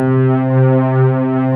BIGPADC4.wav